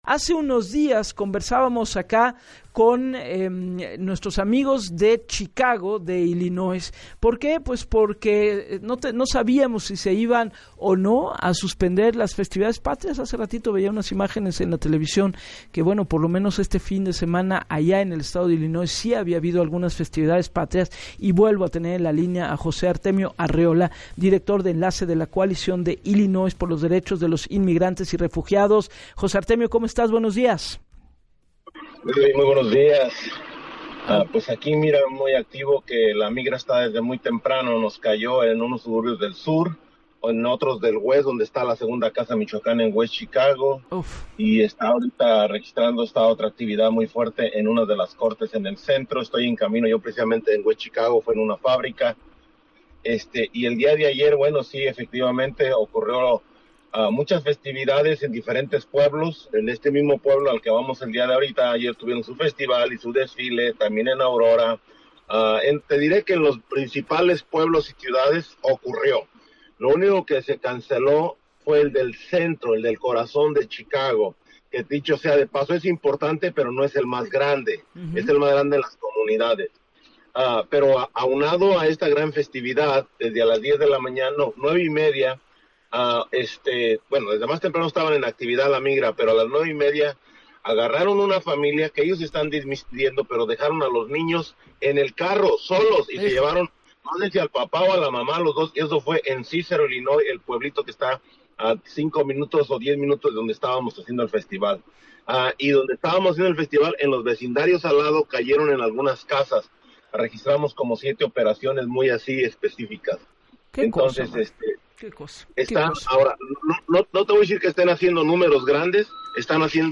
Migrante michoacano murió en operativo de ICE